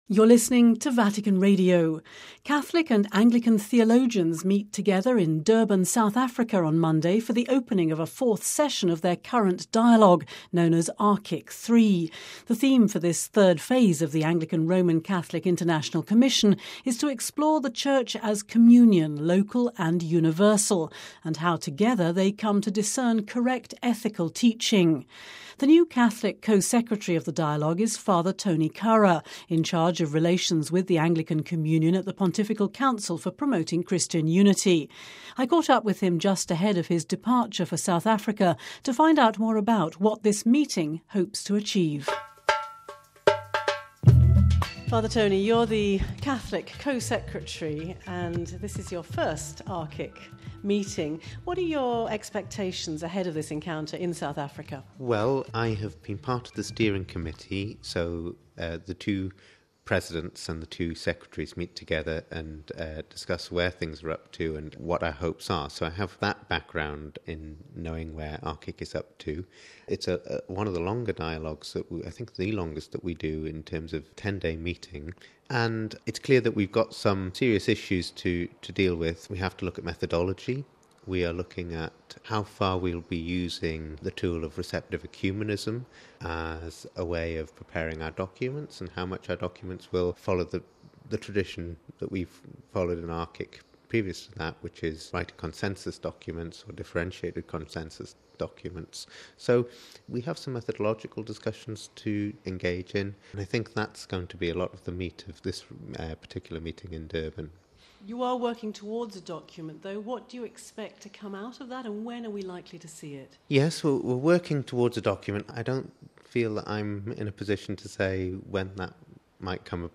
(Vatican Radio) Catholic and Anglican theologians meet together in Durban, South Africa on Monday for the opening of a fourth session of their current dialogue known as ARCIC III. The theme for this third phase of Anglican-Roman Catholic International Commission is to explore the Church as Communion, local and universal, and how, together, they come to discern correct ethical teaching.